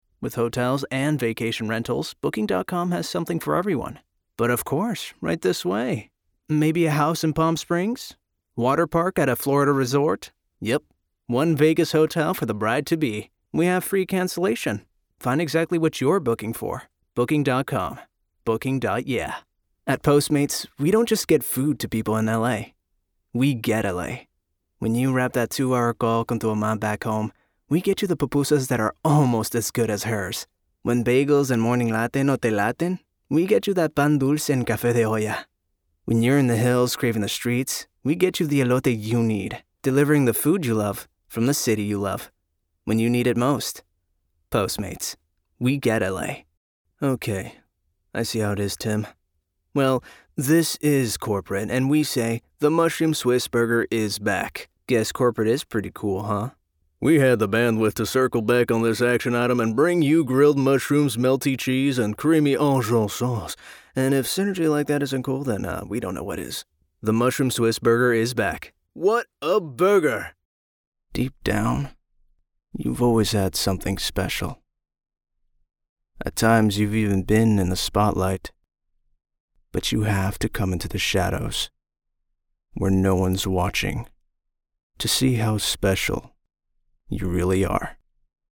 Yng Adult (18-29)